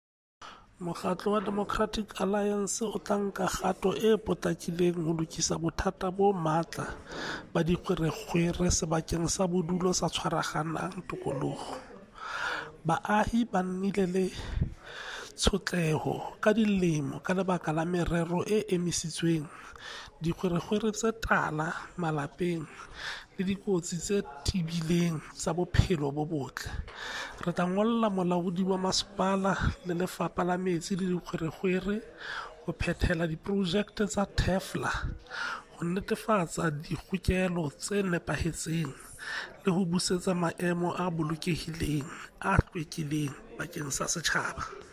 Sesotho soundbites by Cllr Hismajesty Maqhubu.